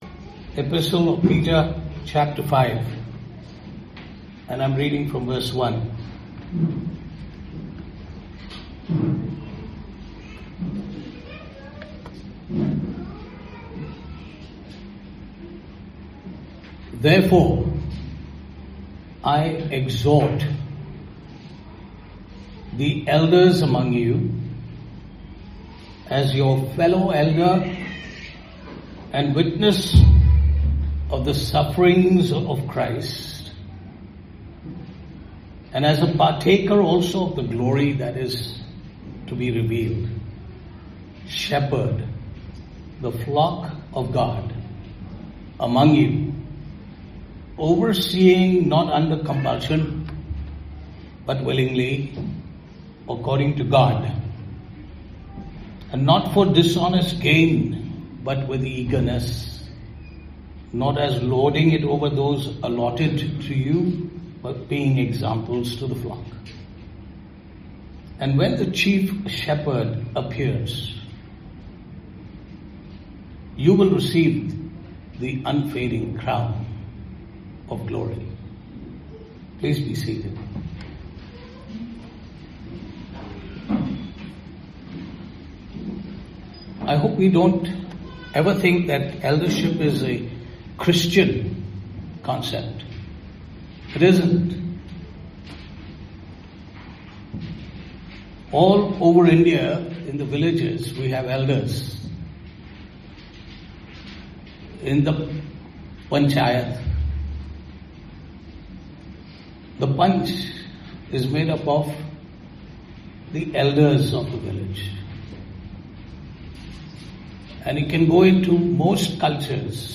Service Type: Sunday Morning
15MarSermon.mp3